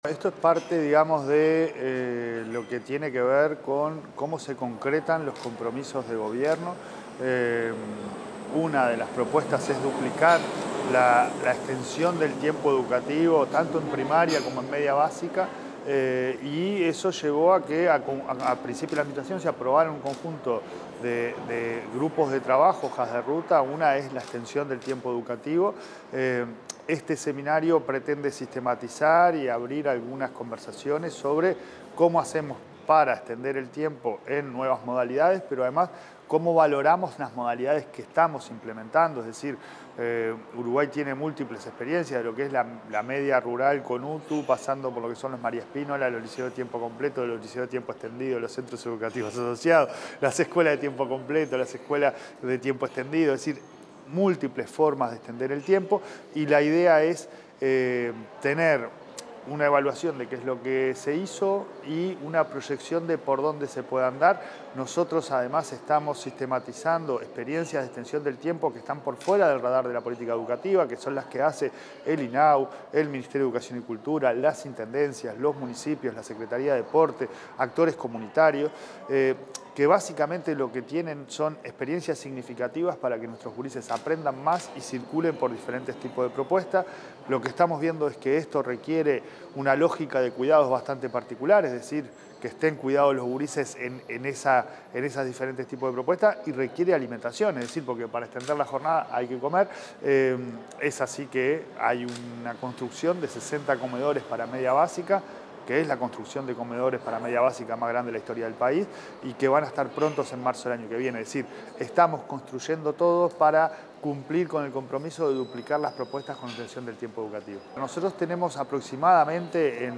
Declaraciones del presidente de la ANEP, Pablo Caggiani